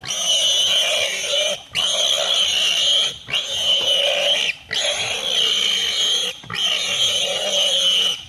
Все записи сделаны в естественной среде обитания животного.
Звук крика бородавочника от боли визг